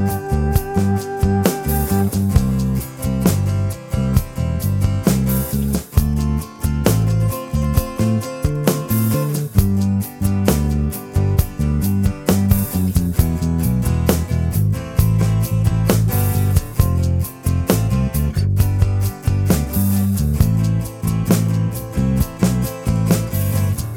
No Rhodes Piano Pop (1970s) 3:53 Buy £1.50